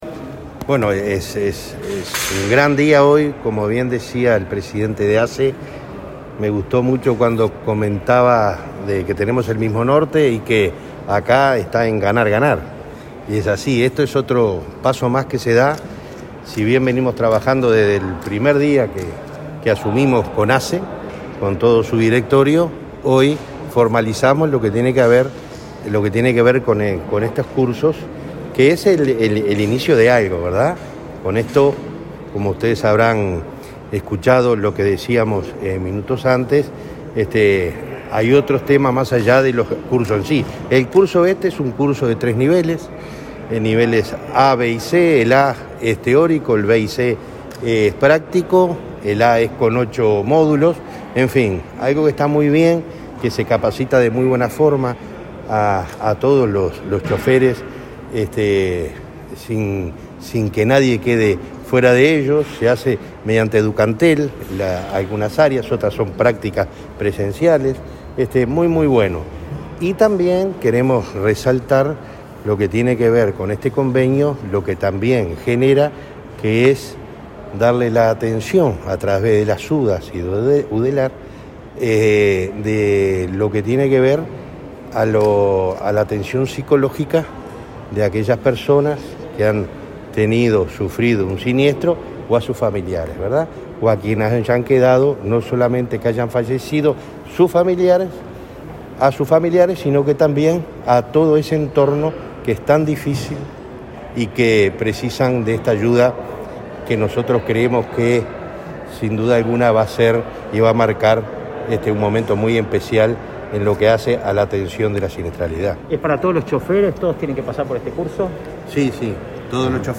Declaraciones del presidente de Unasev, Alejandro Draper
La Unidad Nacional de Seguridad Vial (Unasev) y la Administración de Servicios de Salud del Estado (ASSE) acordaron, este miércoles 11, un convenio marco de cooperación por el cual trabajarán en proyectos articulados entre ambos organismos. En la actividad, el presidente de Unasev, Alejandro Draper, brindó declaraciones a los medios de comunicación para detallar el acuerdo.